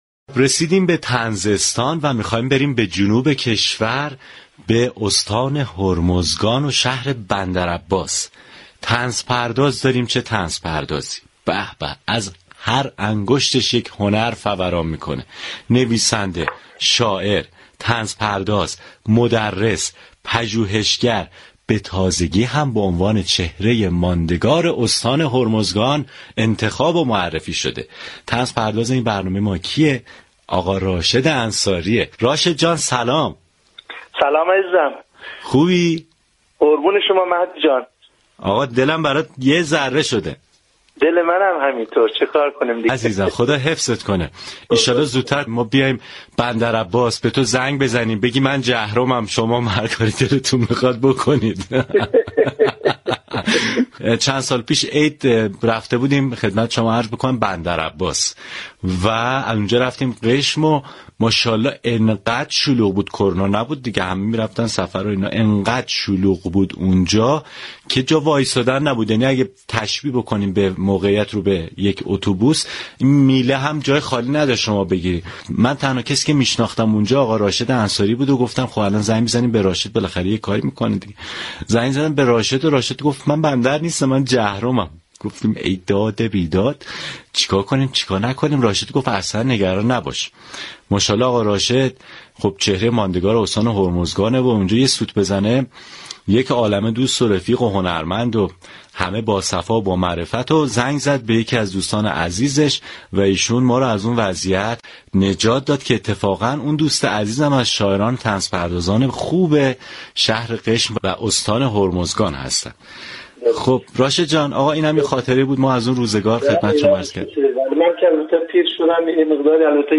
شنونده گفتگوی راشد انصاری طنز پرداز با رادیو صبا باشید.